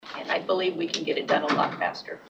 Supervisor Susan Miller voted against the resolution…